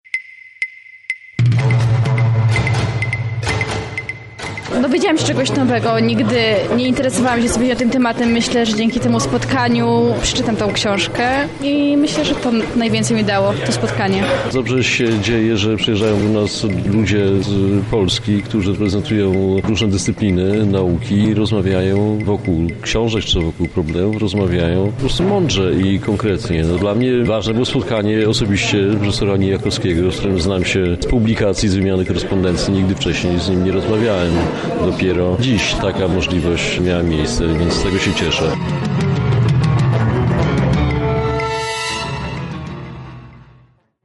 Podczas wydarzenia miała miejsce dyskusja z udziałem badaczy, którzy analizowali problem w aspekcie prawnym i historycznym.
Na miejscu była nasza reporterka: